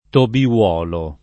vai all'elenco alfabetico delle voci ingrandisci il carattere 100% rimpicciolisci il carattere stampa invia tramite posta elettronica codividi su Facebook Tobiolo [ tobi- 0 lo ] (antiq. Tobiuolo [ tobi U0 lo ]) pers. m. bibl.